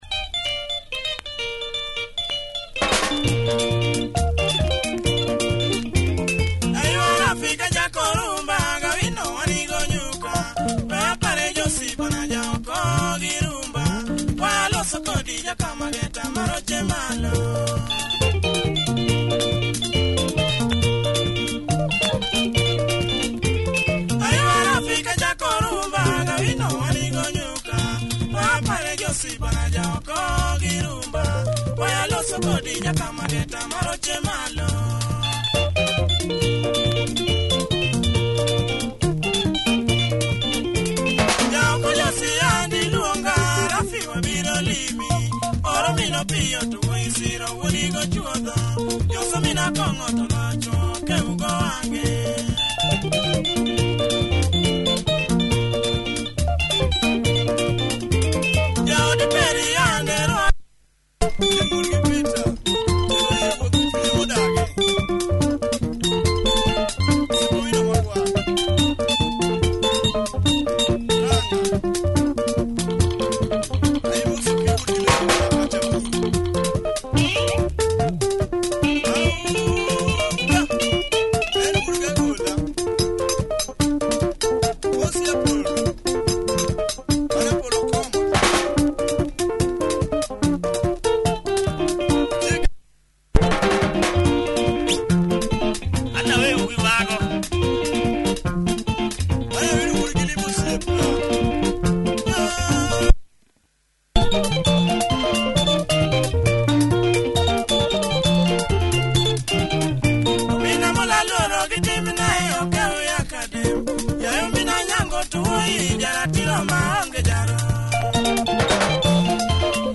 Tight LUO benga, heavy breakdown on the flip side!